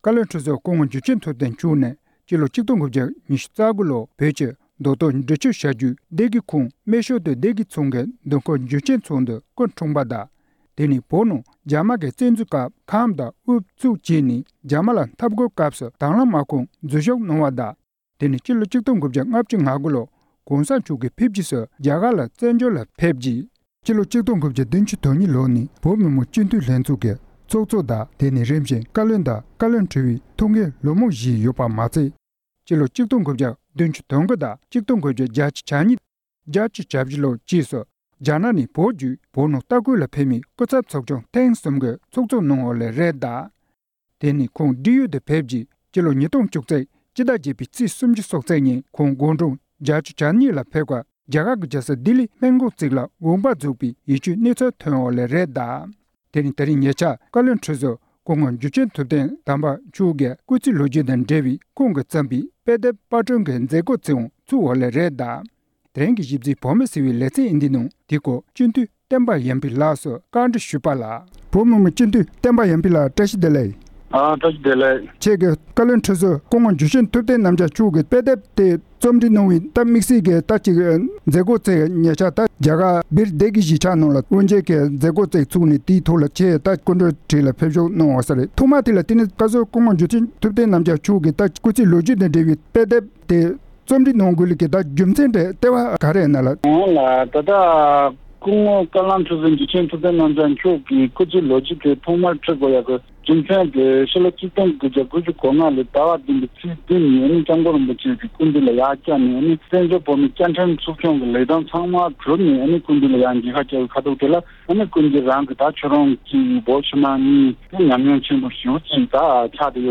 ༄༅། །བཀའ་བློན་ཁྲི་ཟུར་འཇུ་ཆེན་ཐུབ་བསྟན་རྣམ་རྒྱལ་མཆོག་གི་སྐུ་ཚེའི་ལོ་རྒྱུས་དང་འབྲེལ་བའི་ཉེ་རབས་བོད་ཀྱི་བྱུང་བ་བརྗོད་པའི་ལོ་རྒྱུས་གྱི་དཔེ་ཚོགས་པར་དེབ་གྲངས་༢༢པར་སྐྲུན་ལེགས་གྲུབ་ཟིན་ཡོད་པ་དང་། དེས་མ་འོངས་བོད་ཀྱི་ལོ་རྒྱུས་དང་སྤྱི་ཚོགས་ནང་ལེགས་སྐྱེས་དང་བག་ཆགས་ཇི་ཙམ་འཇོག་ཐུབ་མིན་གྱི་སྐོར་འབྲེལ་ཡོད་མི་སྣ་དང་གནས་འདྲི་ཞུས་པ་ཞིག་གསན་རོགས་གནང་།།